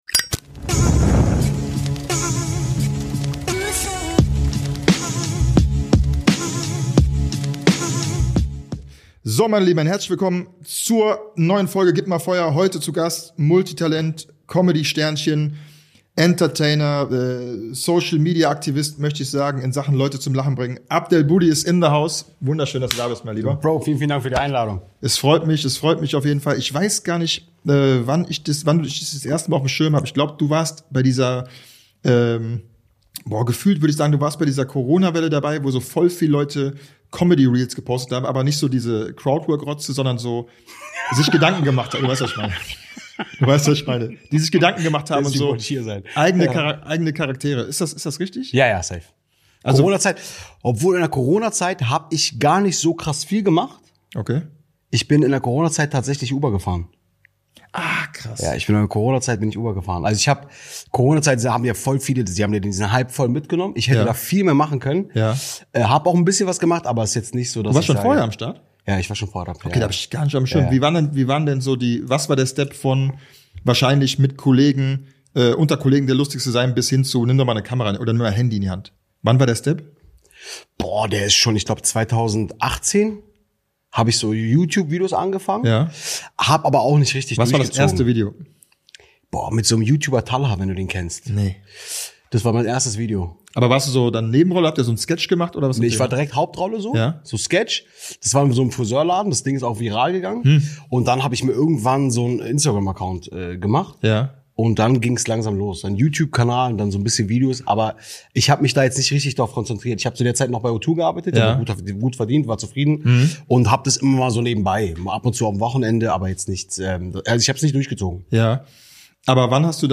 Vielleicht die lustigste Folge bis jetzt, weil man einfach alles und jeden so geil imitieren kann. Und das Ganze sogar Freestyle live on stage. Wir reden über alles, außer ernste Themen.